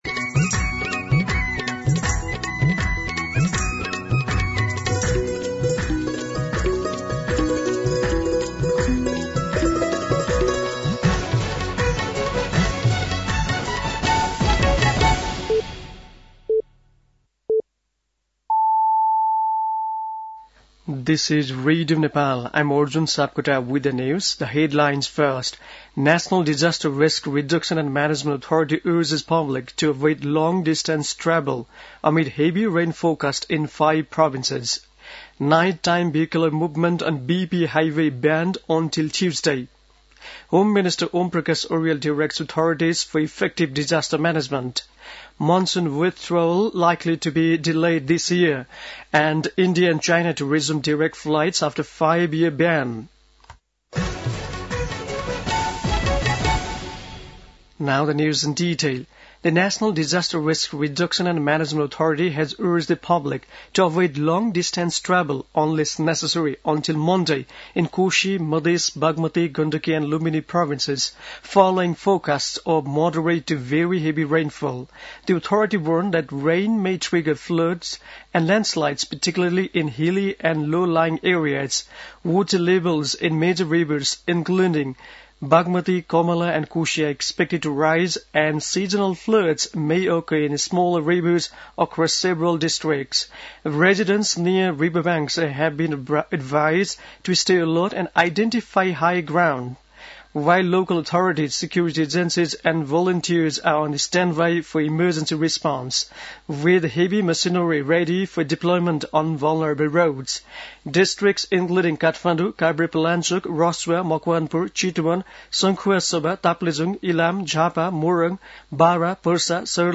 दिउँसो २ बजेको अङ्ग्रेजी समाचार : १७ असोज , २०८२
2-pm-english-News.mp3